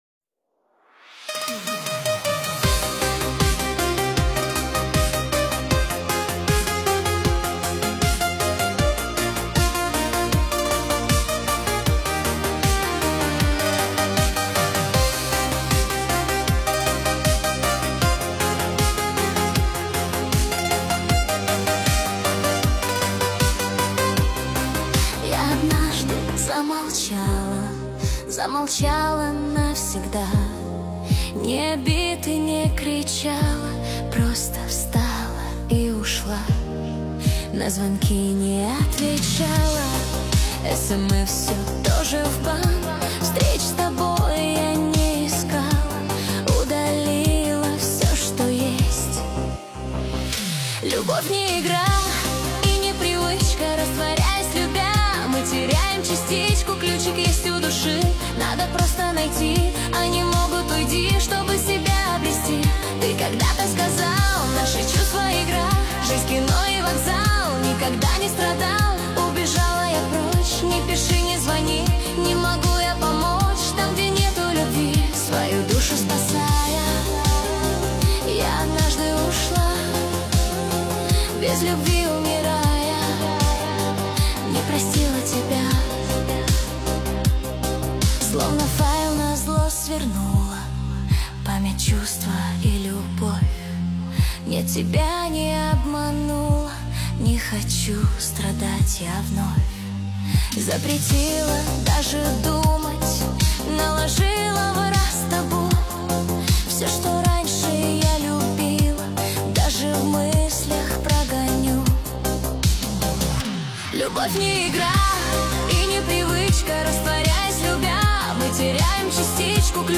Качество: 320 kbps, stereo
Песни Суно ИИ, Нейросеть Песни 2025
Песня ИИ нейросеть